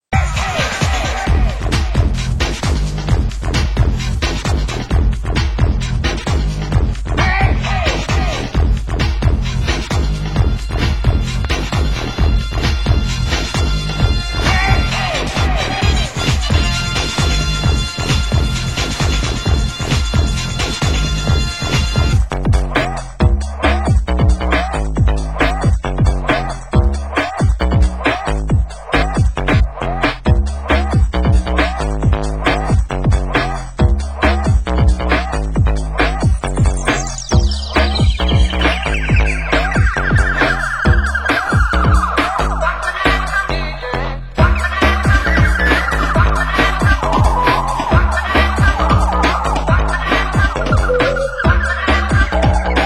Genre: Break Beat
breakstep Mix